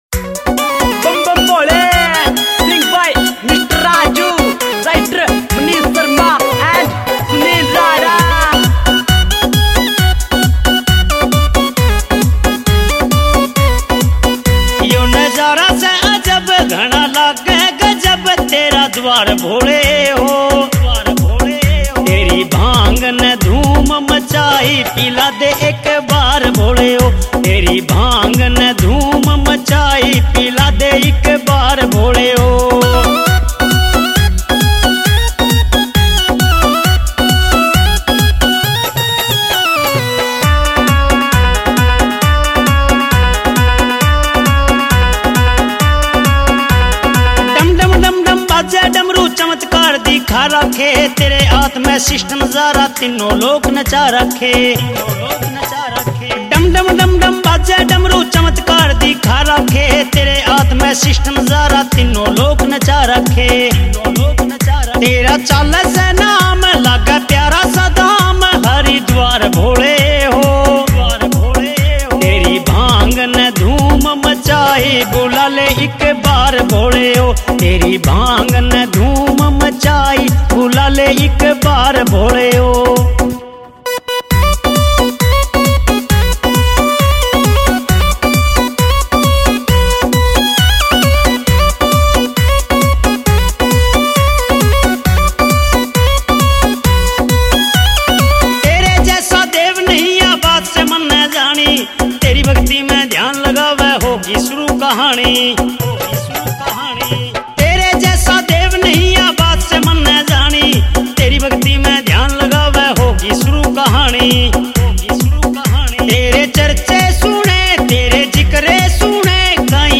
Bhakti Songs
» Haryanvi Songs